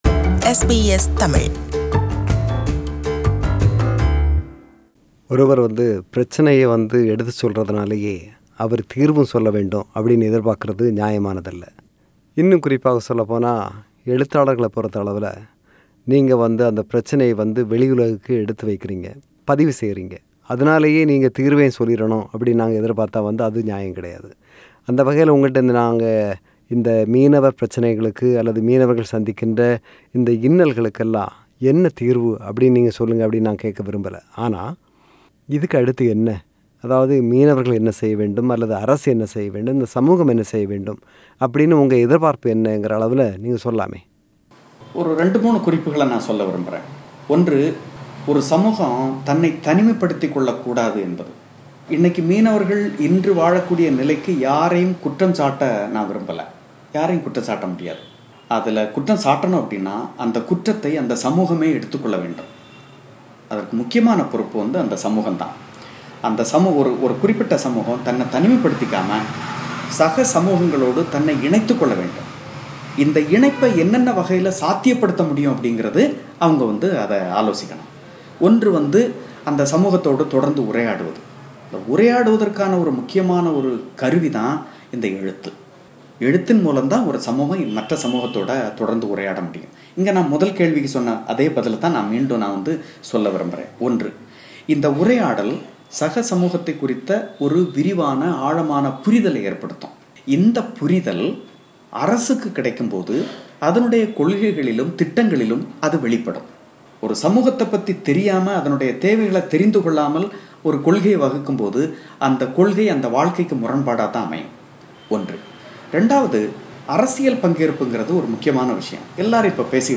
இல்லம் சென்று அவரை சந்தித்து நாம் நடத்திய உரையாடலின் நிறைவுப் பாகம்.